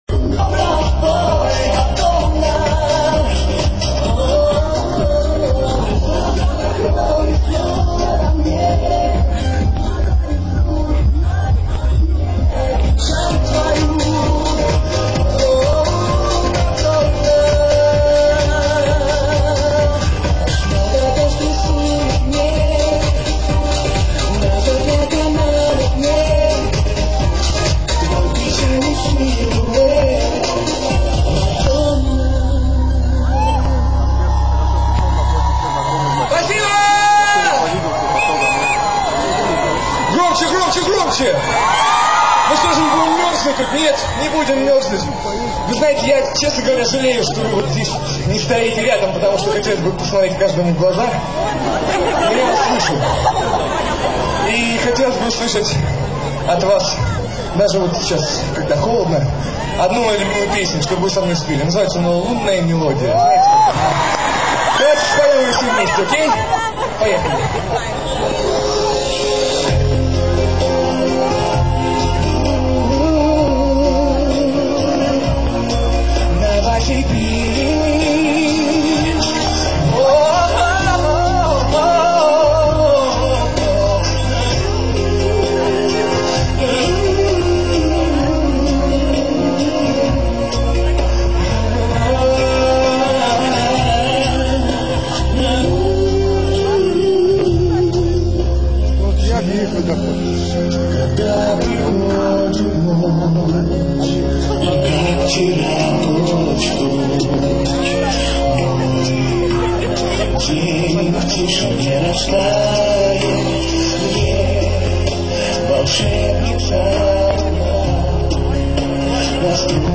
НОВОСИБИРСК, ПЛОЩАДЬ ЛЕНИНА, 4 НОЯБРЯ 2005 ГОДА